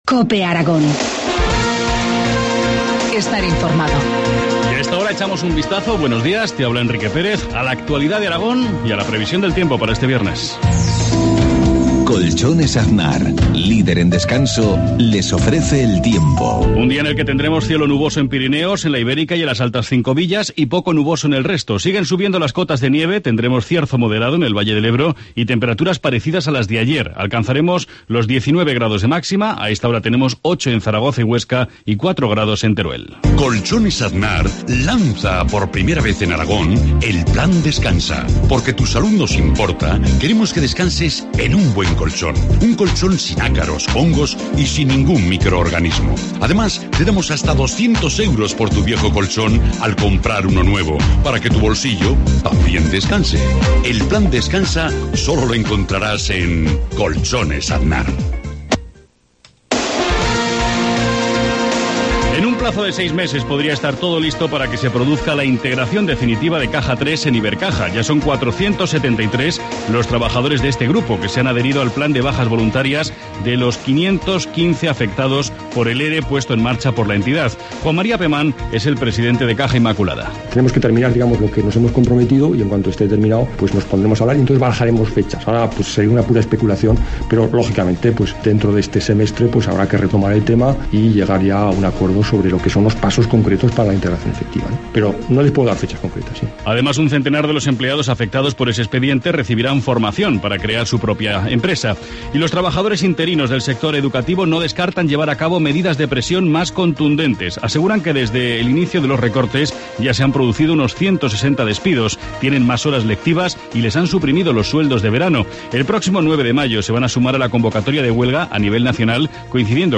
Informativo matinal, viernes 3 de mayo, 7.53 horas